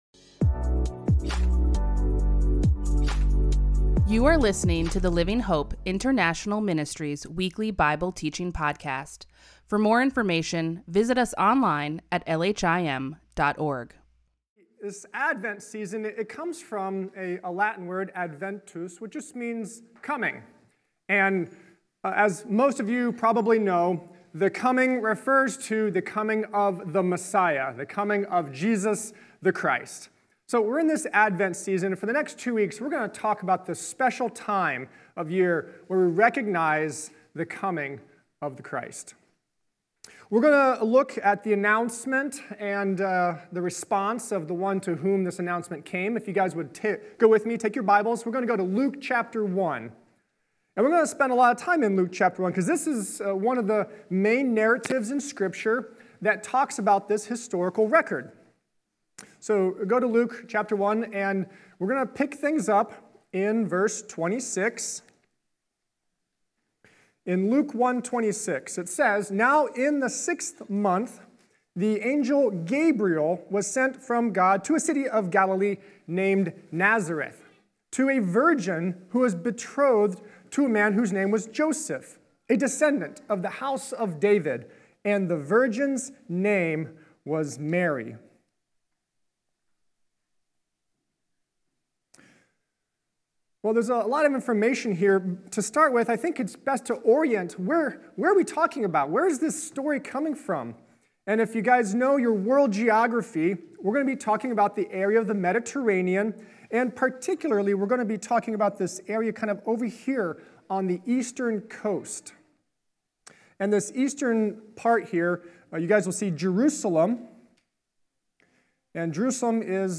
LHIM Weekly Bible Teaching